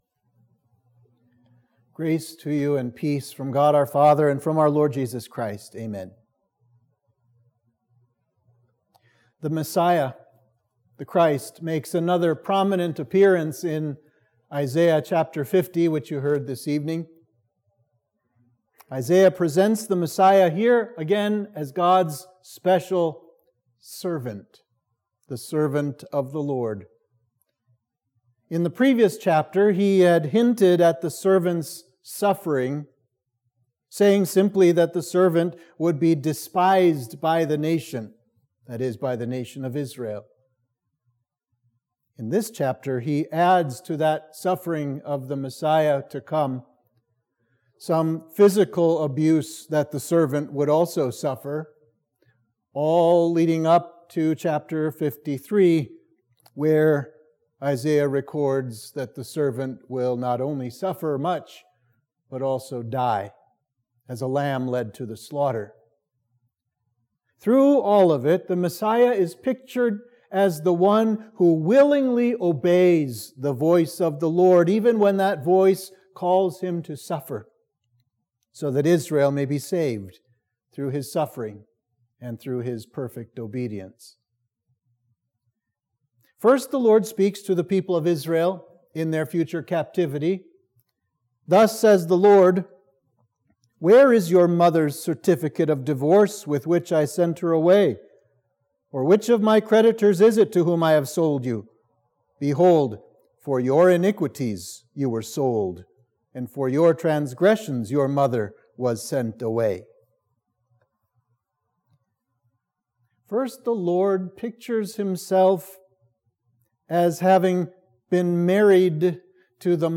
Sermon for Midweek of Trinity 8